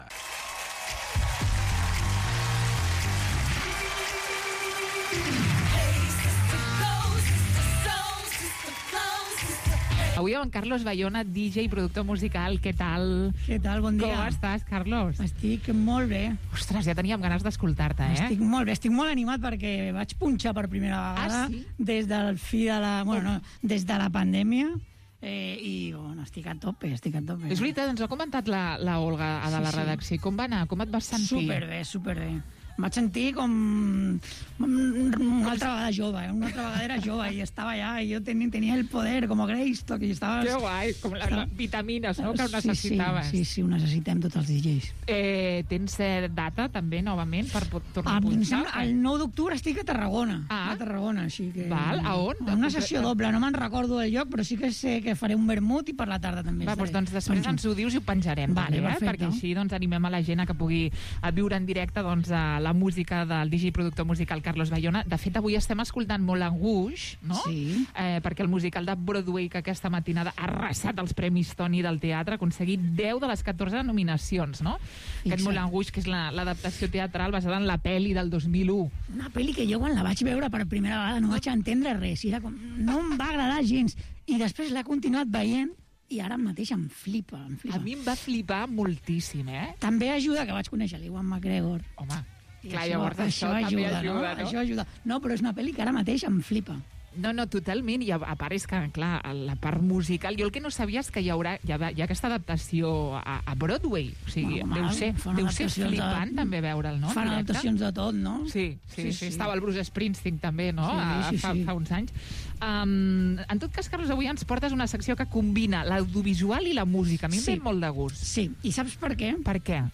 Info-entreteniment